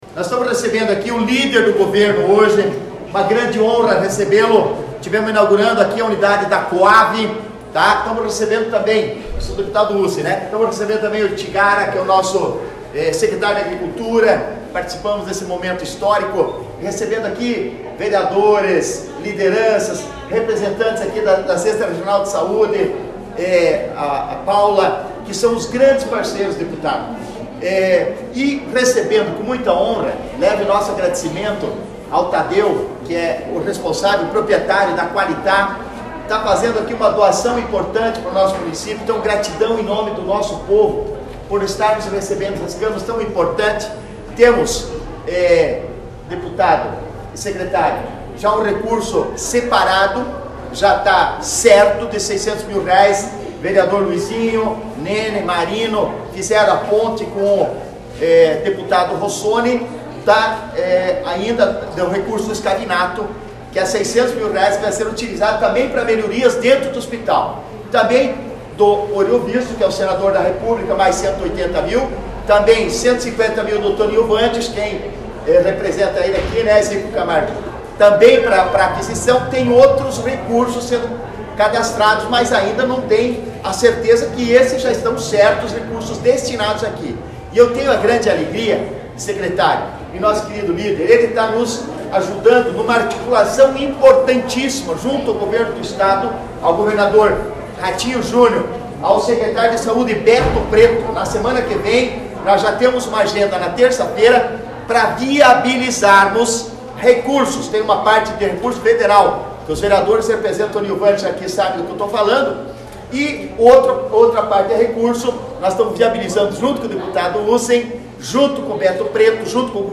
Acompanhe o áudio do prefeito Antonio abaixo: